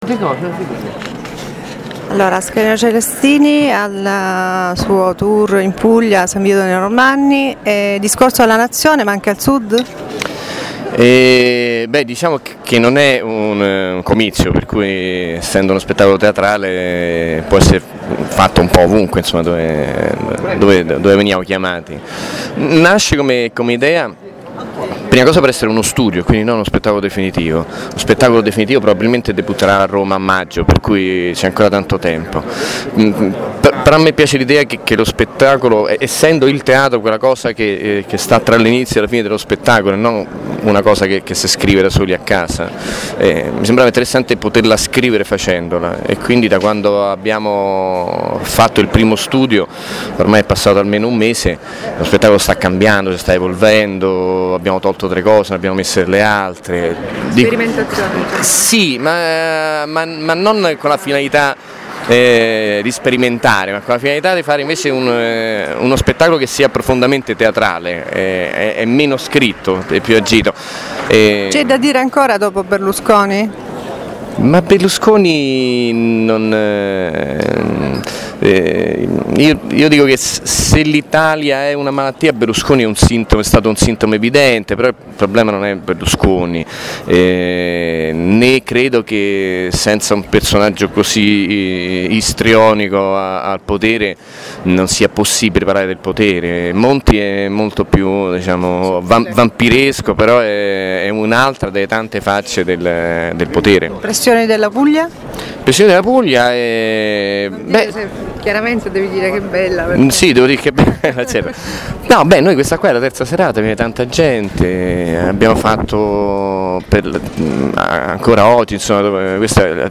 Sarcastica e spietata la lettura di Ascanio Celestini delle vicende italiane, vecchie e nuove. L'attore, ospite della villa comunale di S. Vito dei Normanni, ha fatto il ritratto di una nazione dalle mille contraddizioni in cui il potere ha assunto vesti diverse nel corso dei decenni ma che, comunque, ha impedito di crescere civilmente, al popolo italiano.